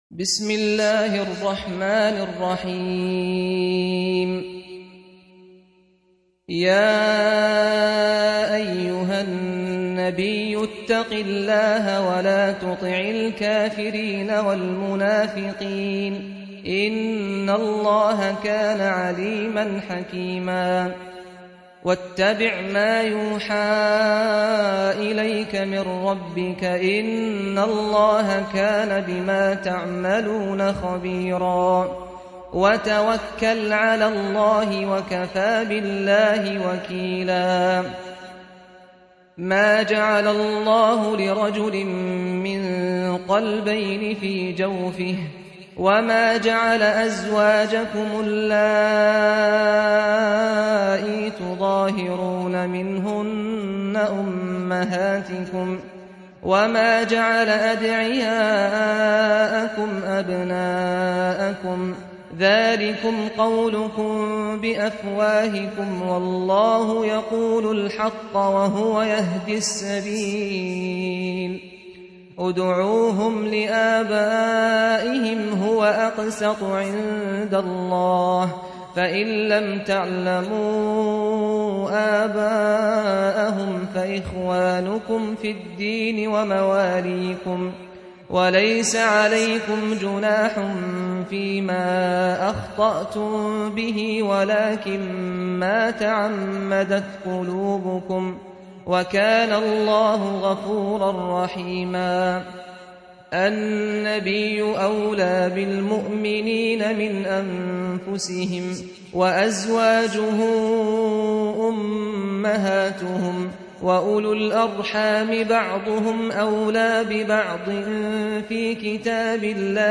Surah Repeating تكرار السورة Download Surah حمّل السورة Reciting Murattalah Audio for 33.